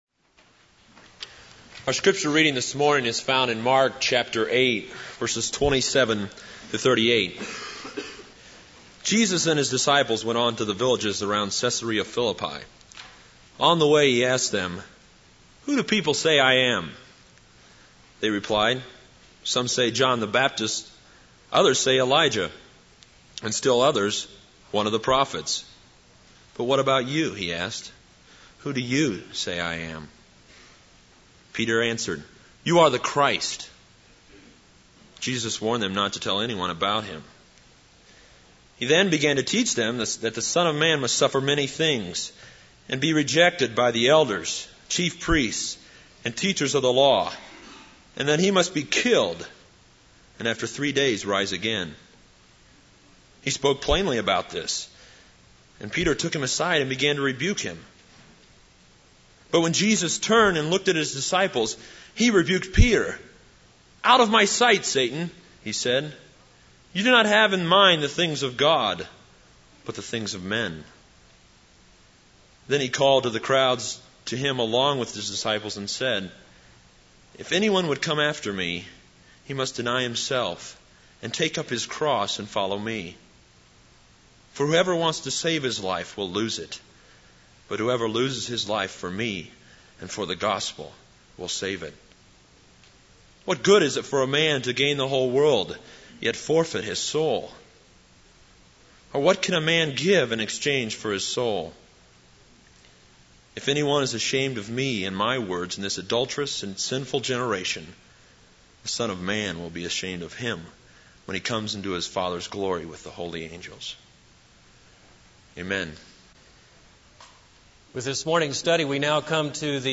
This is a sermon on Mark 8:27-38.